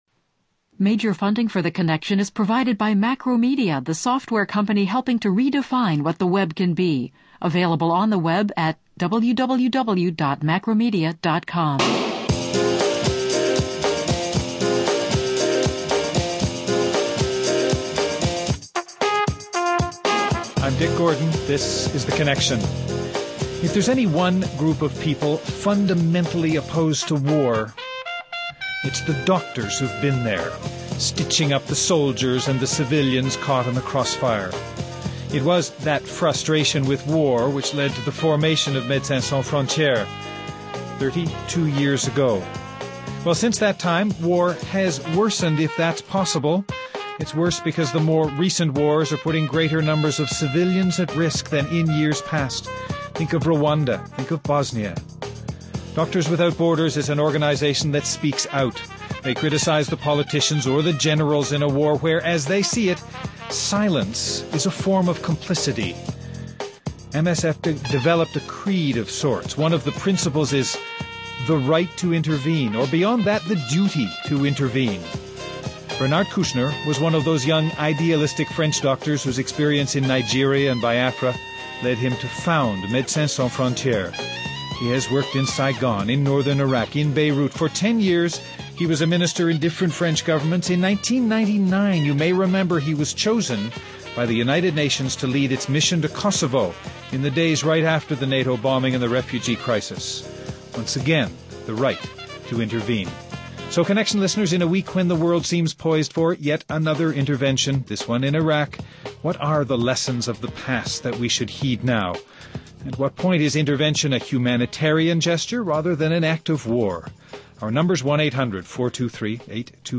Guests: Dr. Bernard Kouchner, former Head of the U.N. Interim Administrative Mission in Kosovo, former French Minister of Health and founder of Medecins Sans Frontieres.